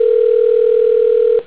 callerring.wav